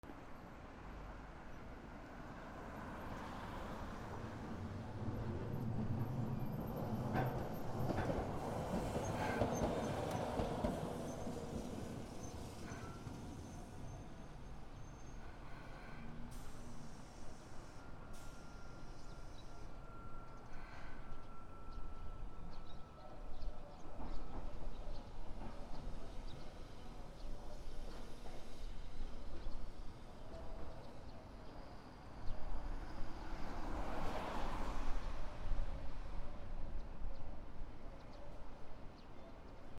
路面電車 オフ気味
広島 D50